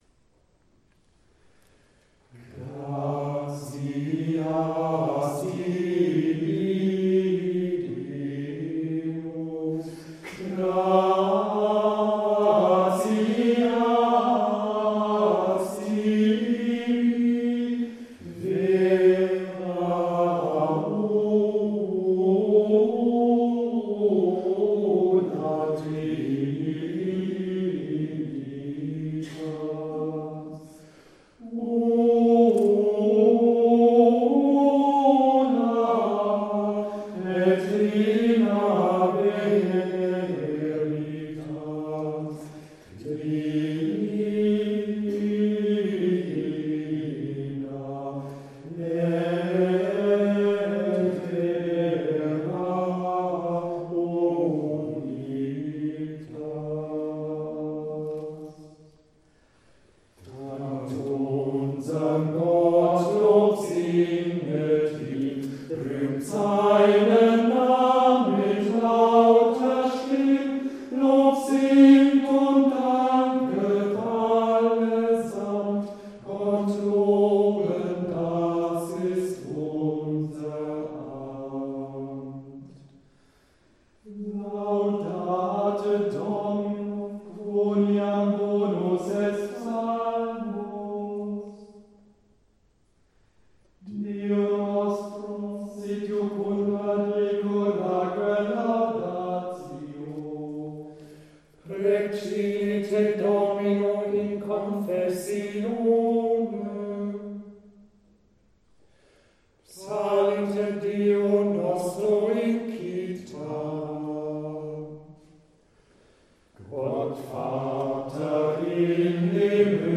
Pfingsten 2022
Antiphon „Gratias tibi, Deus“ mit Versen aus Psalm 147